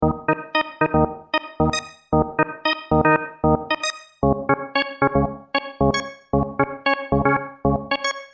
Organ (2), 131 KB
tb_organ_2.mp3